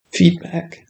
screen-reader-audio
Feedback.wav